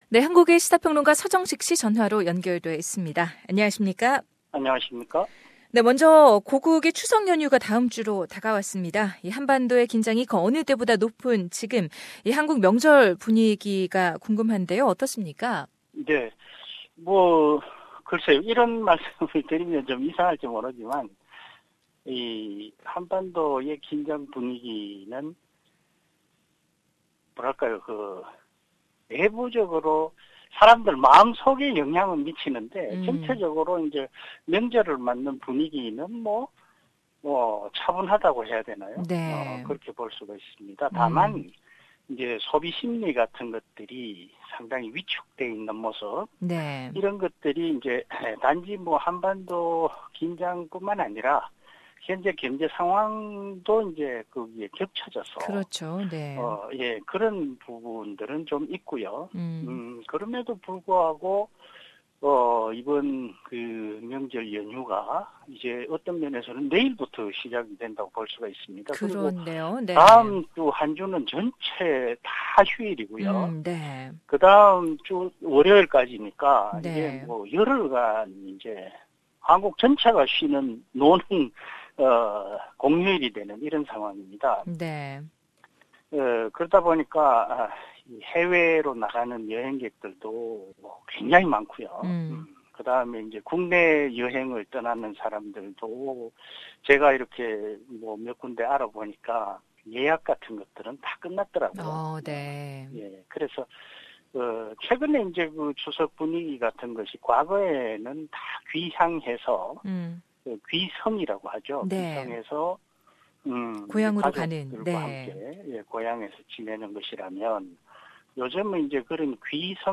SKIP ADVERTISEMENT Full interview is available on Podcast above.